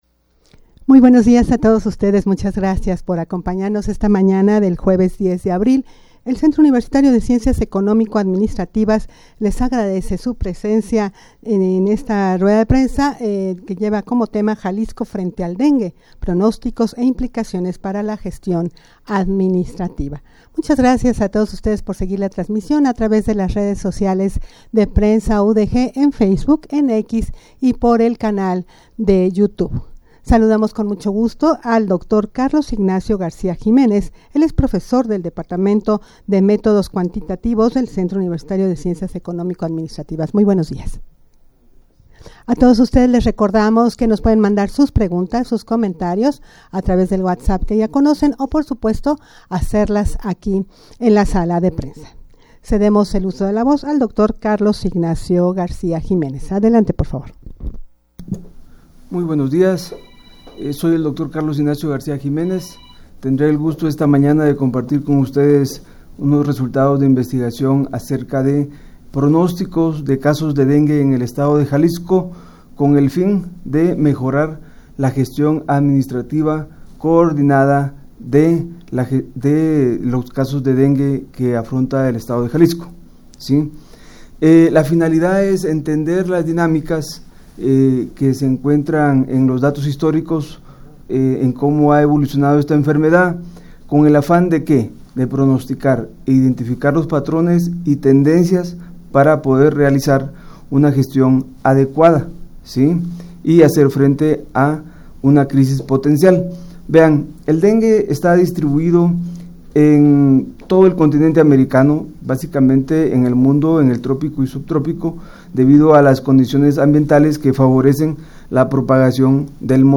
Audio de la Rueda de Prensa
rueda-de-prensa-jalisco-frente-al-dengue-pronostico-e-implicaciones-para-la-gestion-administrativa.mp3